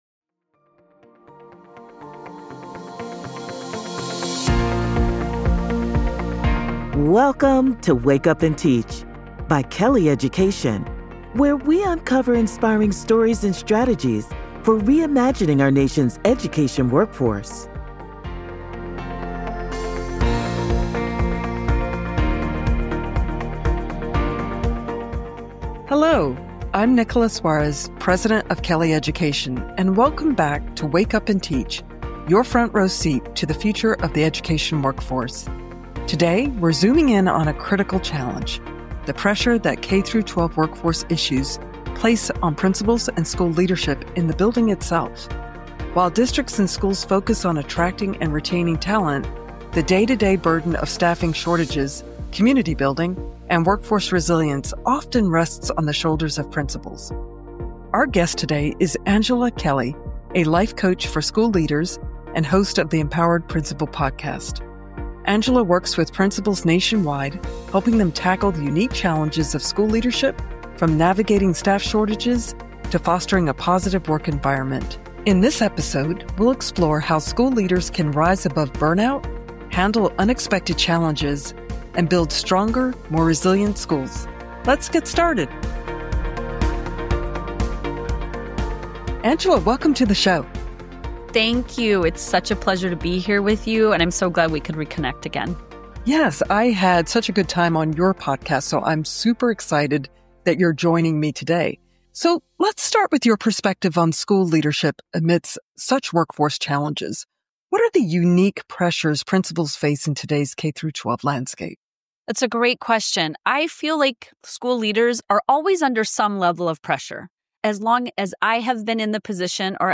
live from the PLC at Work® Institute in Seattle, Washington, for an energizing conversation